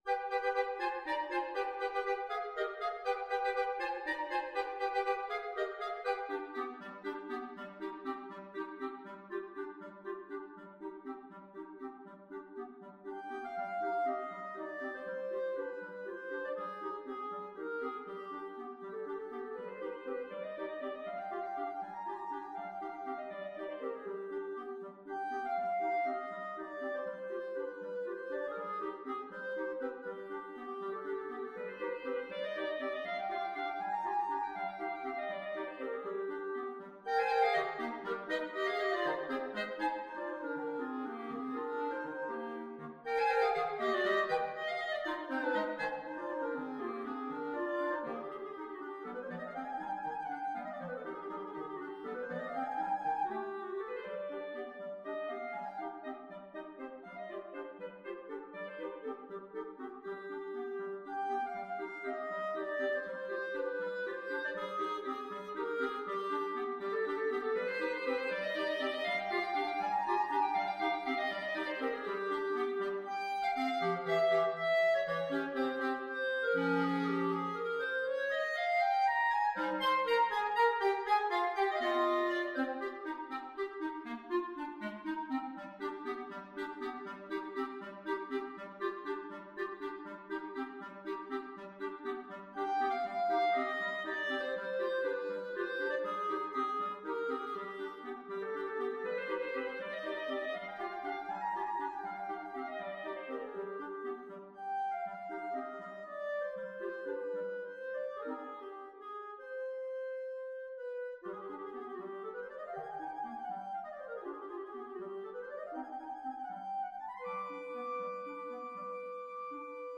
Clarinet 1Clarinet 2Clarinet 3
3/8 (View more 3/8 Music)
Allegro vivo (.=80) (View more music marked Allegro)
Classical (View more Classical Clarinet Trio Music)